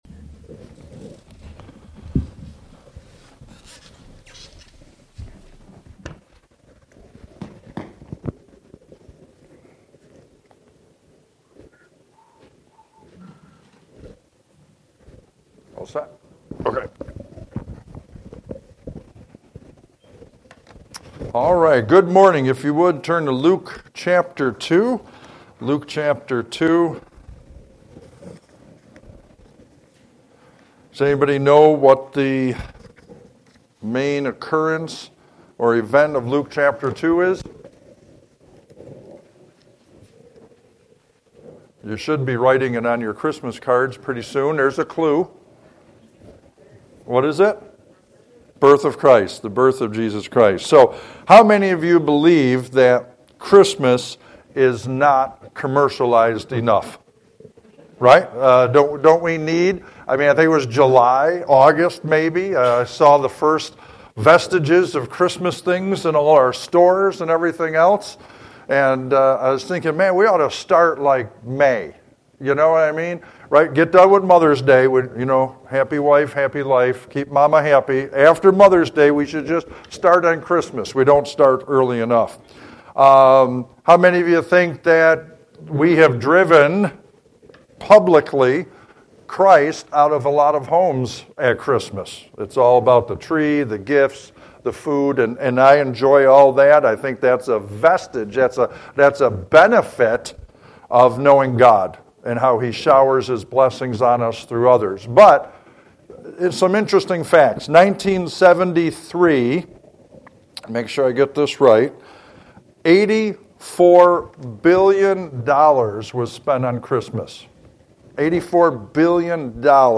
Sermons by First Baptist Church of Elba